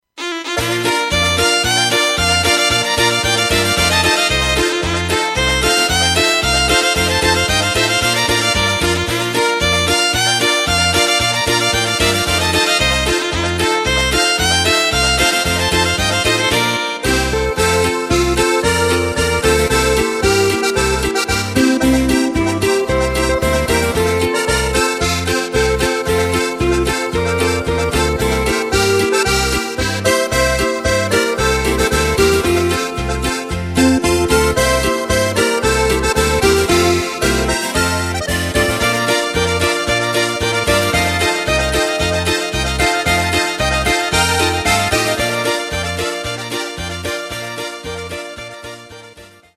Takt:          2/4
Tempo:         113.00
Tonart:            Bb
Boarischer aus dem Jahr 2009!